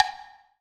BHIWOODBL.wav